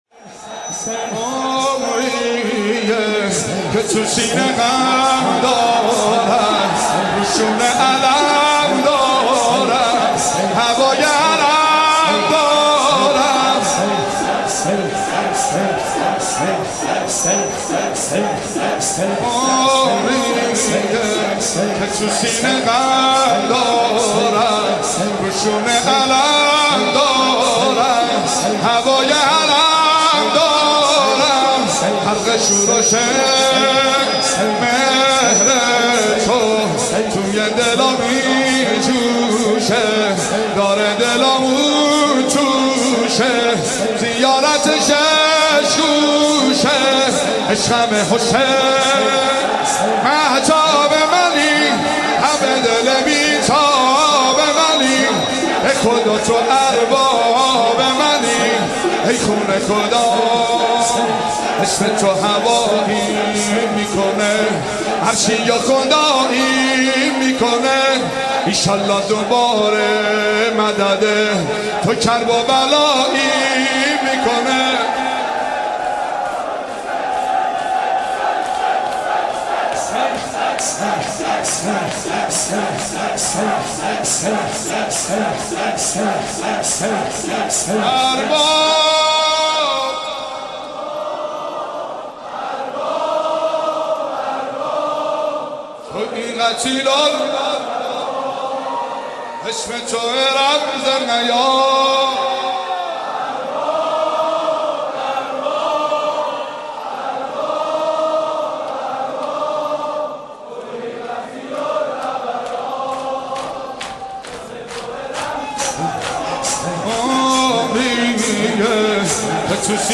مداحی ویژه پیاده روی اربعین